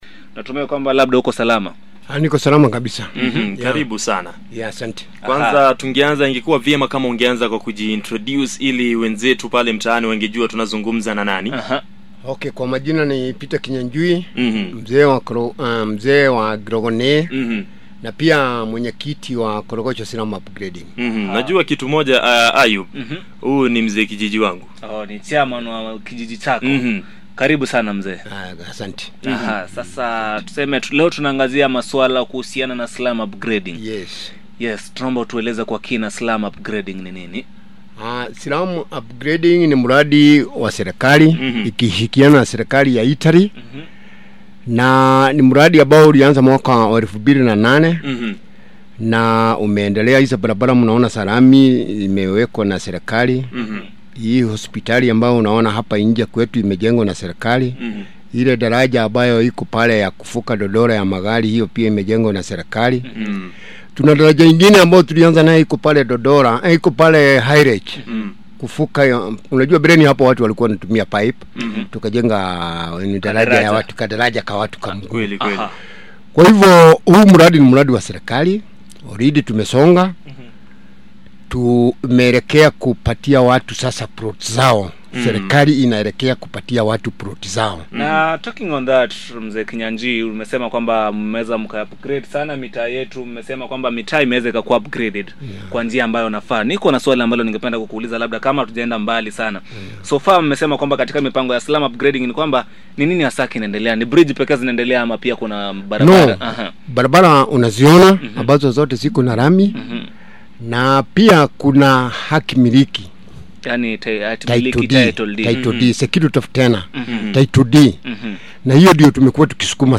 Koch FM interview concerning slum upgrading.